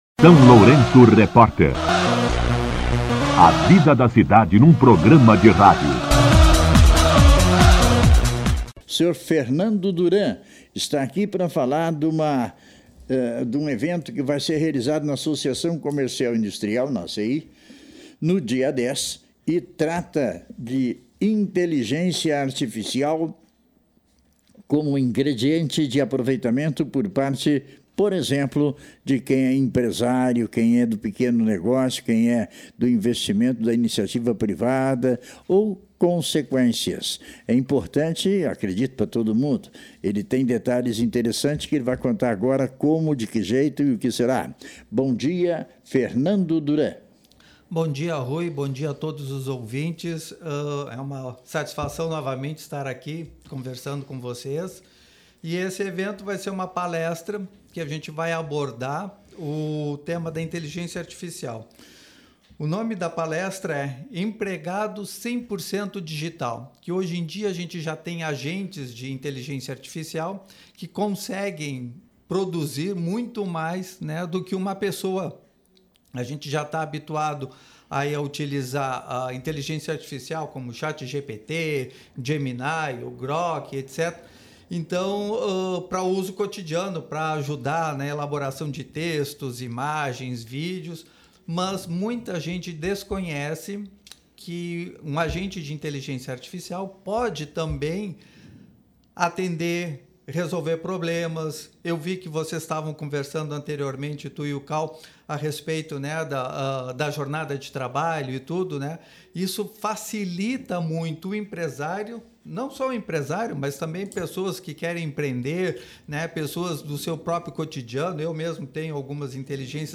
Entrevista com um Criador de agentes de IA